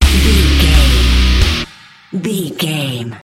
Aeolian/Minor
E♭
drums
electric guitar
bass guitar
hard rock
lead guitar
aggressive
energetic
intense
nu metal
alternative metal